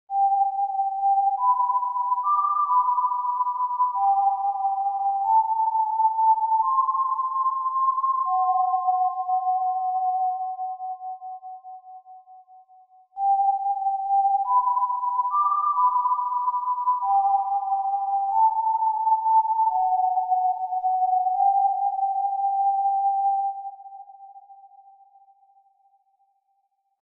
why does that whistle send a chill up my spine ;-;